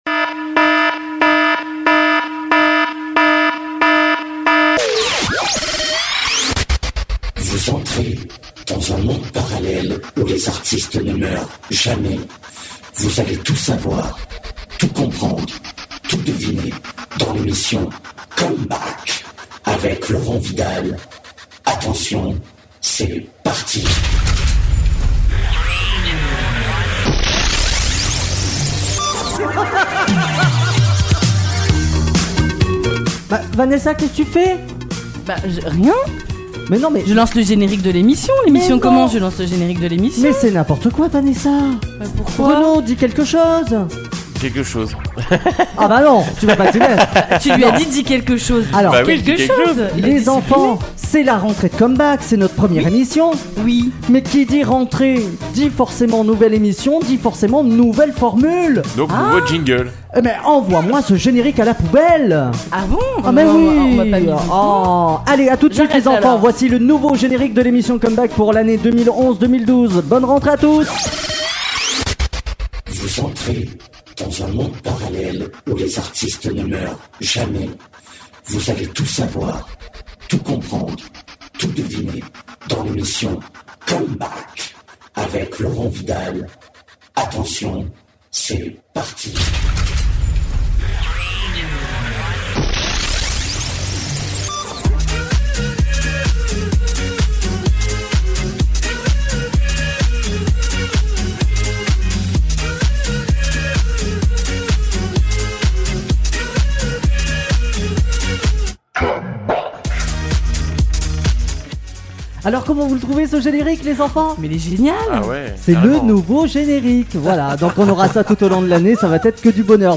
Ou télécharger l’émission avec l’interview de Joniece Jamison
Joniece Jamison dans les studios d’Otoradio Mais Joniece Jamison est bien plus qu’un duo.